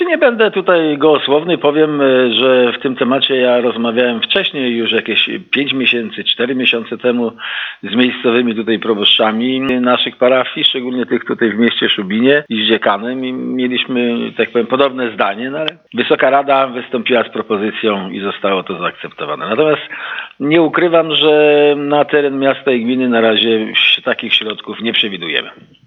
Mówił ponownie burmistrz Ignacy Pogodziński.